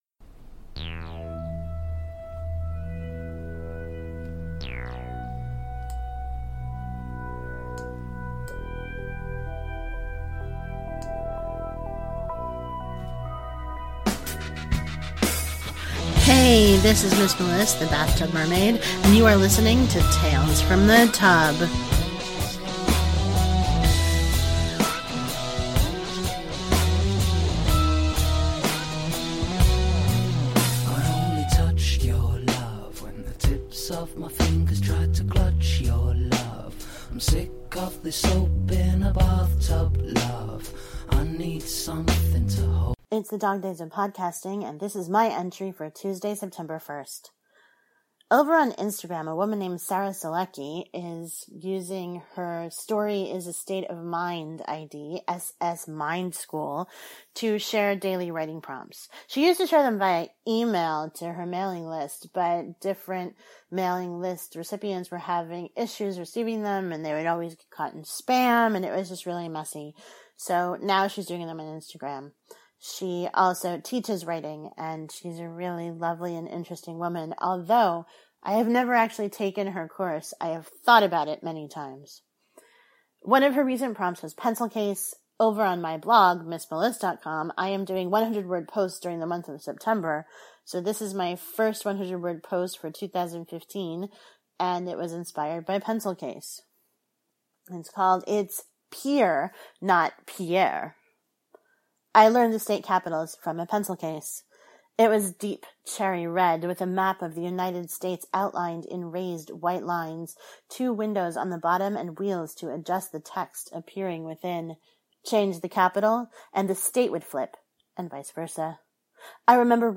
Opening: “Soap in a Bathtub” by Stoney
Closing Music: “You Can Use My Bathtub” by Little Thom